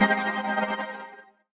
lightimpact.wav